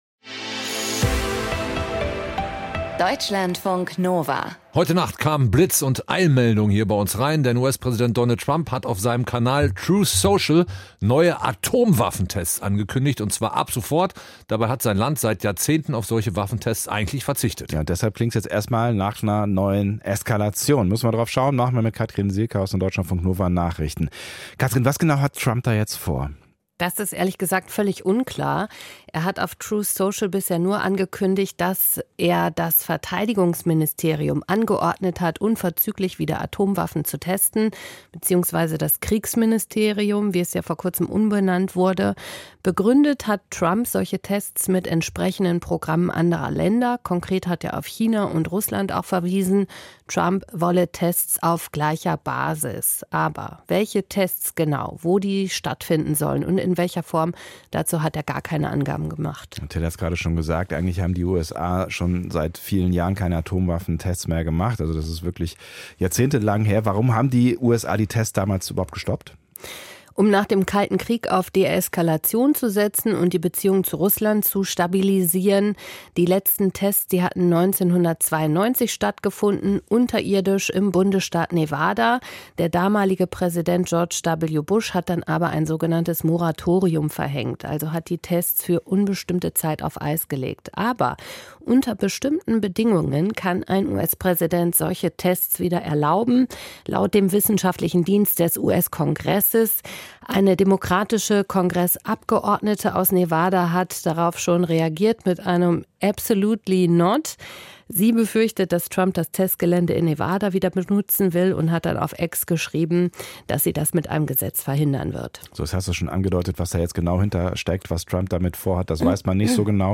Kommentar zu US-Atomwaffentests: Eine leere Geste